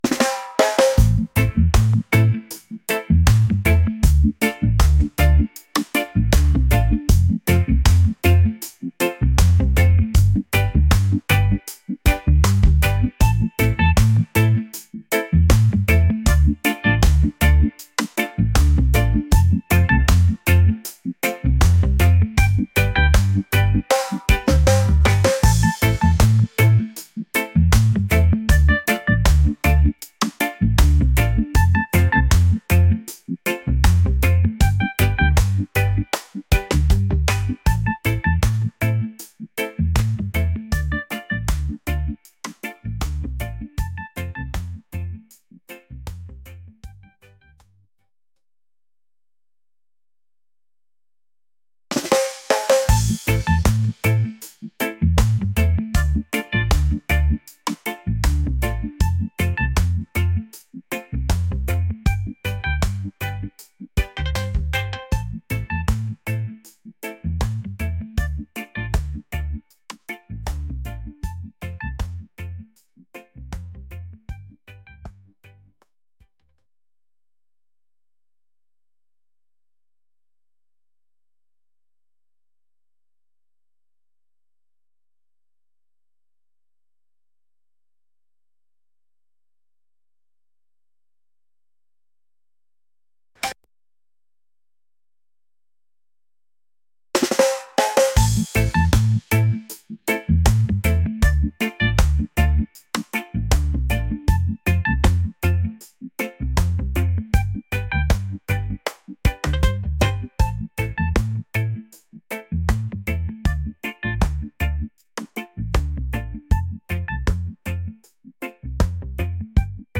upbeat | reggae | laid-back